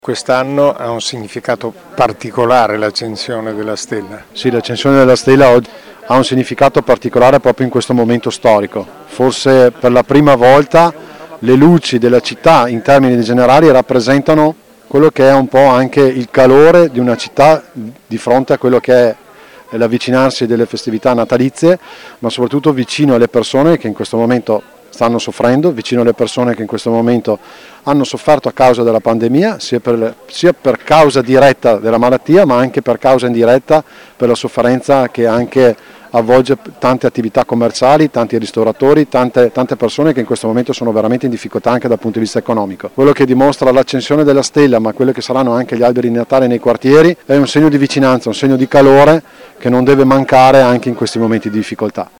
Le interviste del nostro corrispondente
assessore all’Economato Luca Zanotto
assessore-allEconomato-Luca-Zanotto-accensione-della-stella-di-Natale.mp3